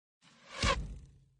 arrow.mp3